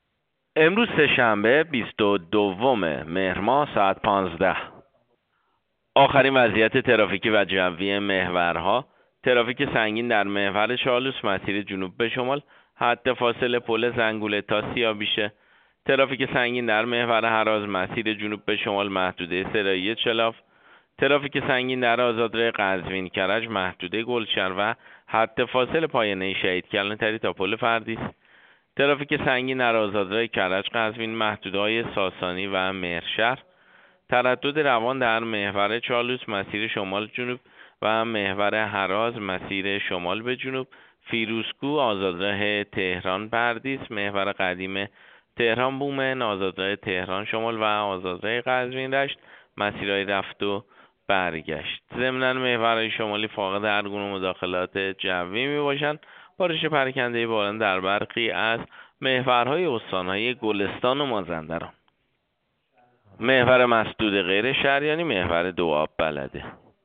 گزارش رادیو اینترنتی از آخرین وضعیت ترافیکی جاده‌ها ساعت ۱۵ بیست‌ودوم مهر؛